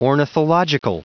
Prononciation du mot ornithological en anglais (fichier audio)
Prononciation du mot : ornithological